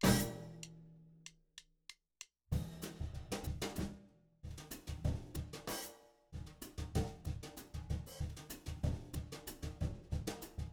It's a loud drum sound before the count-off click.
ps Don't worry that the actual drum part sounds all wrong; it's a samba in 3/4 I'm experimenting with.
RealDrumsafterDragnDrop.WAV